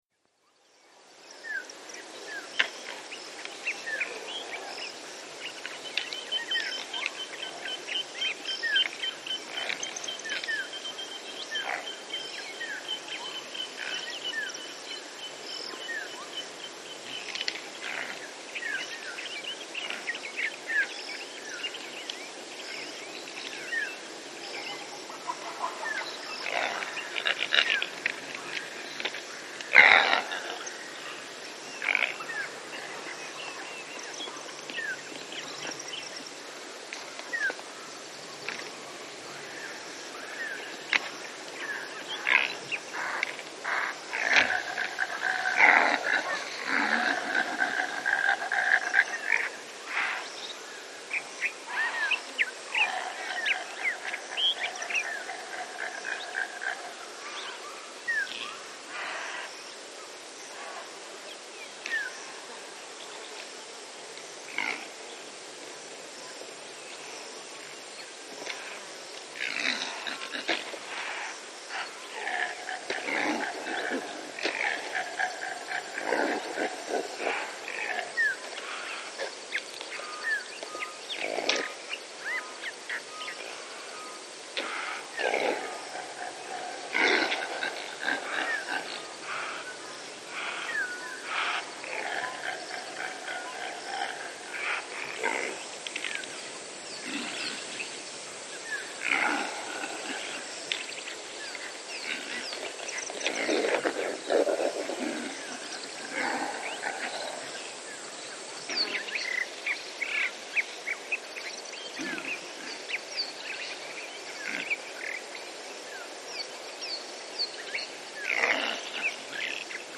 Записи сделаны в дикой природе и передают атмосферу саванны.
Африканская антилопа импала